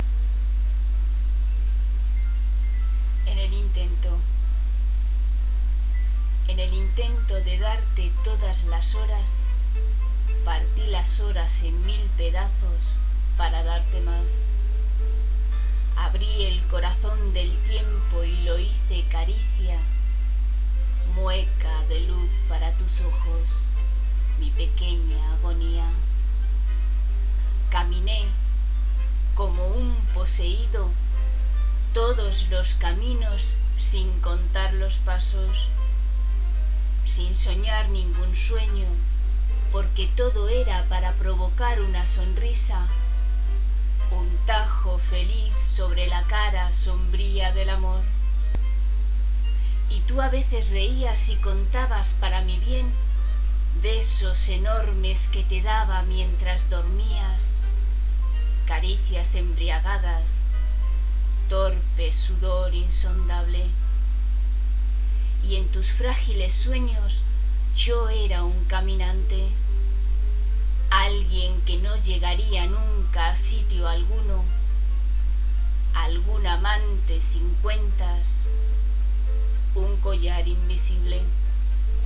recita